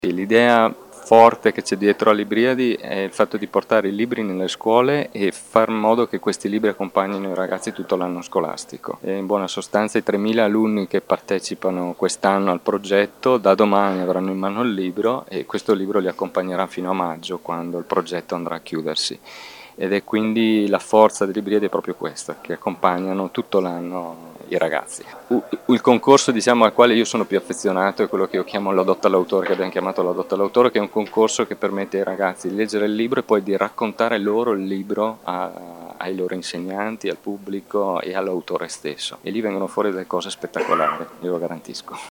ha intervistato alcuni dei presenti alla conferenza stampa: